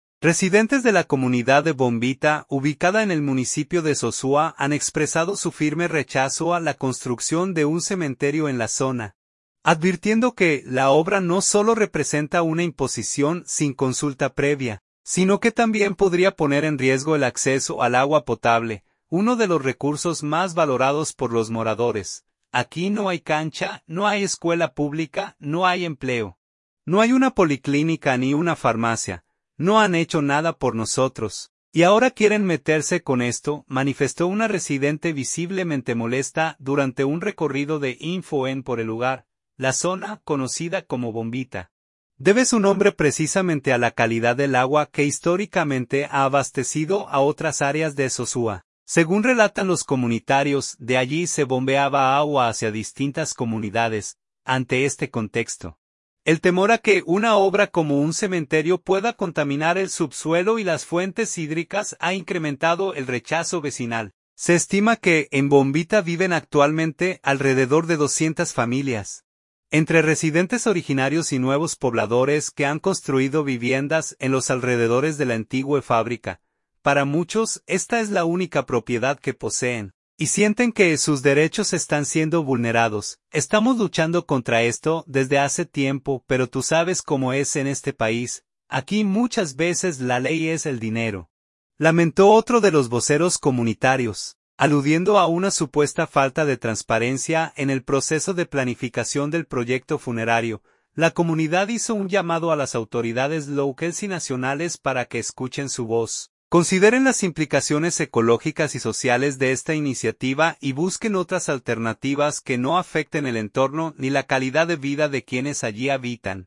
“Aquí no hay cancha, no hay escuela pública, no hay empleo, no hay una policlínica ni una farmacia. No han hecho nada por nosotros, y ahora quieren meterse con esto…”, manifestó una residente visiblemente molesta durante un recorrido de InfoENN por el lugar.